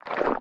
snd_slimesummon.ogg